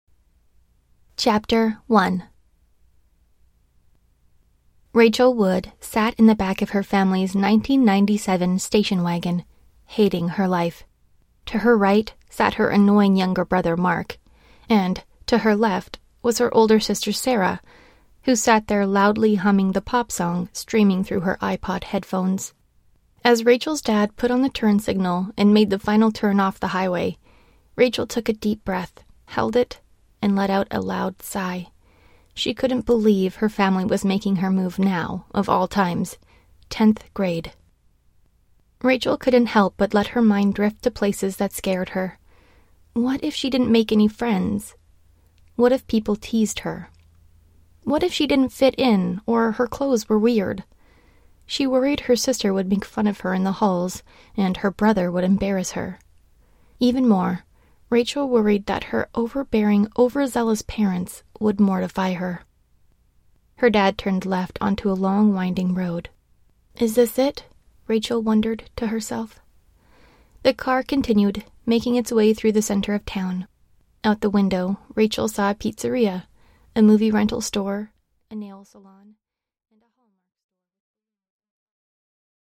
Аудиокнига Sworn | Библиотека аудиокниг